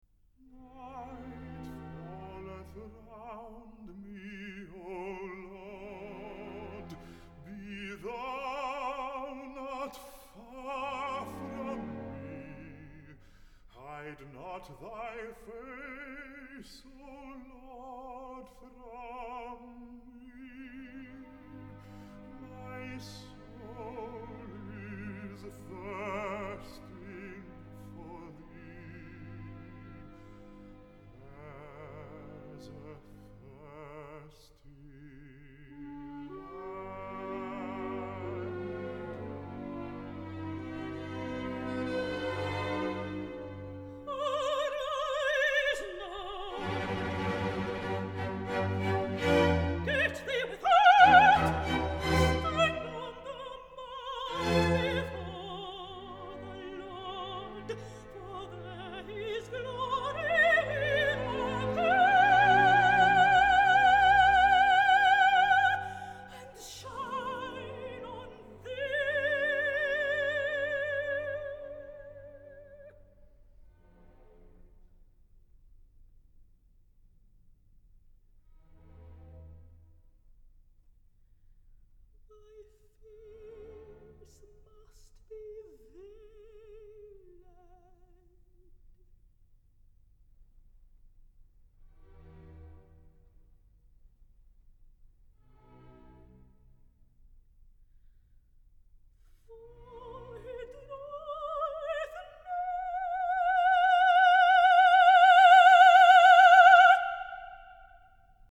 오라토리오 <엘리야> Elijah   Op.70
레치타티브(엘리야, 천사) - 밤이 나를 에워싸는구나. 오, 신이여.
elijah_35_recitative.mp3